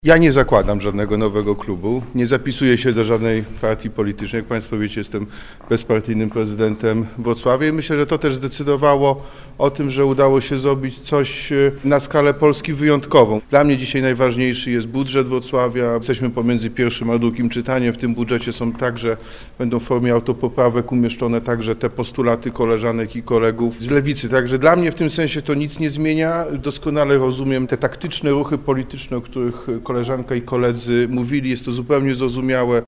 Środowisko Lewicy i Jacek Sutryk potwierdzili kontynuację współpracy na rzecz miasta. Prezydent tłumaczy, że jest spokojny o dalsze losy.